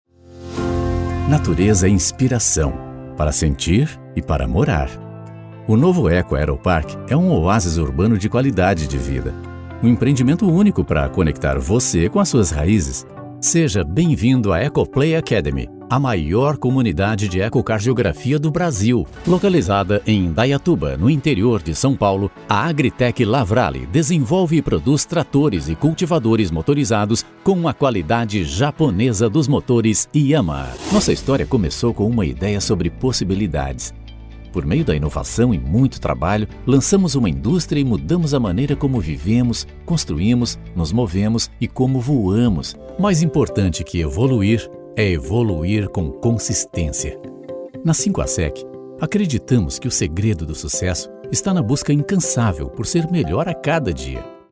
Male
Corporate Videos
Voz grave, natural e conversada, suave e clássico
BaritoneBassDeepLow